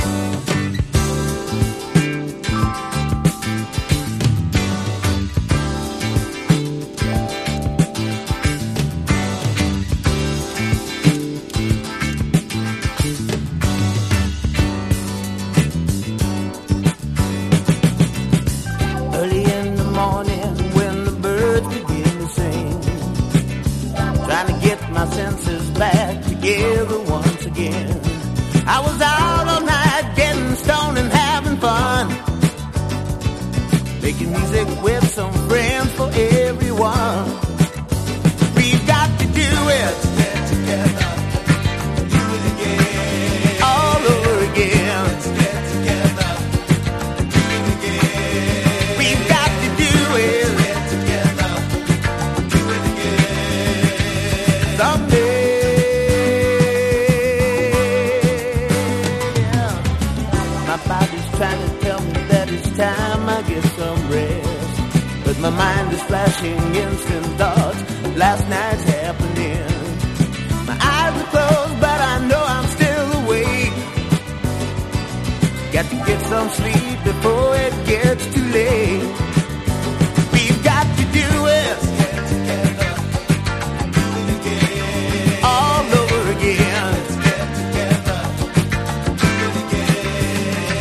ブリージンなハワイアンA.O.R./メロウ・ソウル